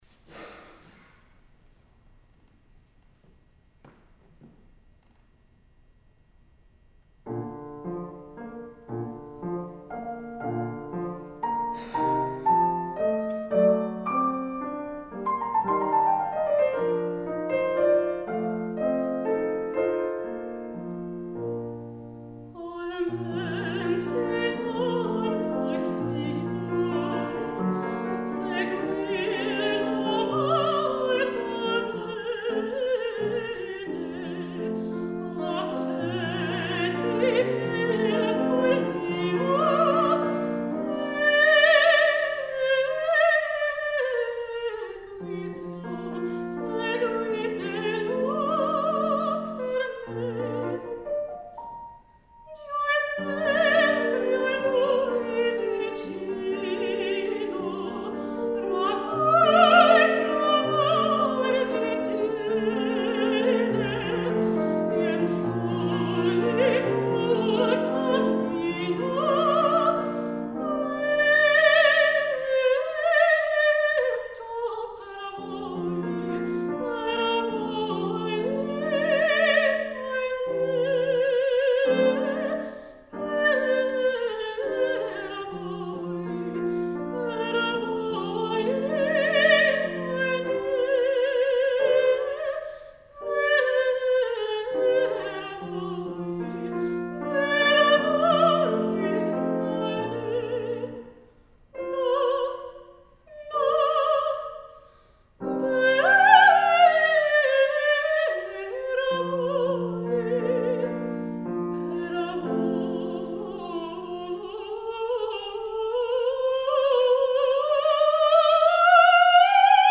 Mezzo-Soprano
Senior Recital